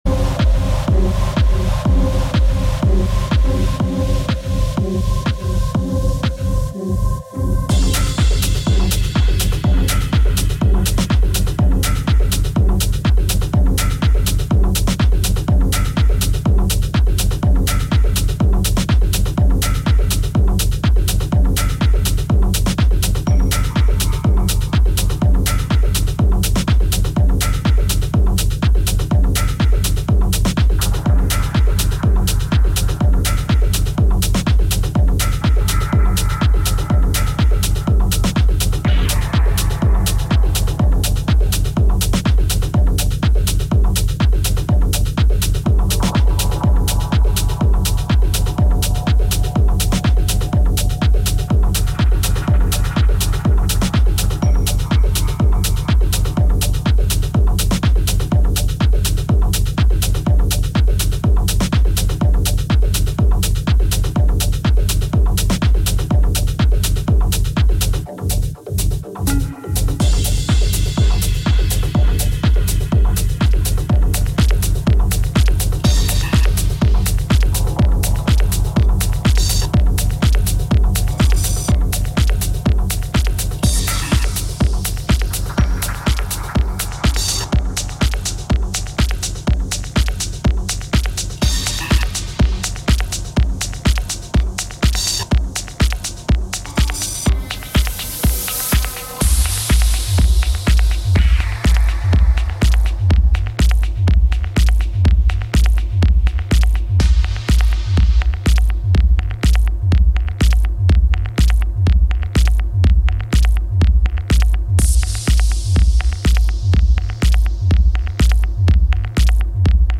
recorded on the roof of the Z Hotel
power driving techno sounds